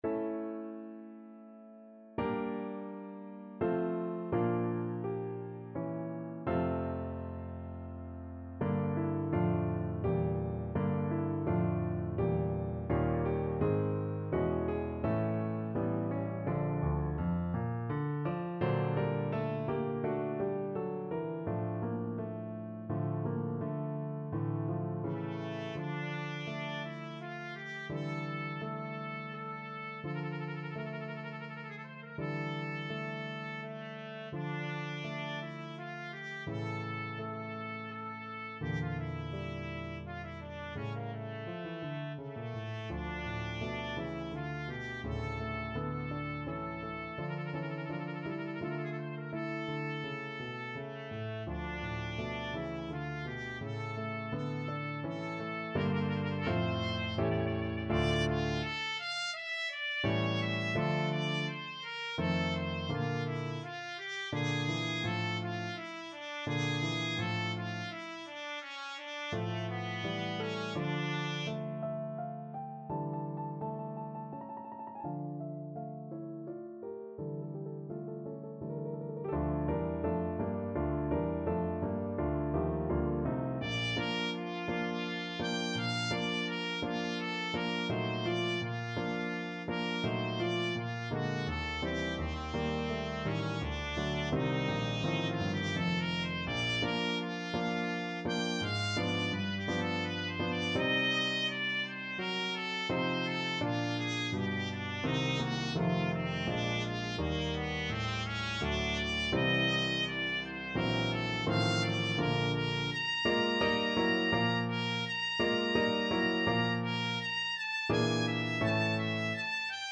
3/4 (View more 3/4 Music)
F4-Bb6
Andante =84
Classical (View more Classical Trumpet Music)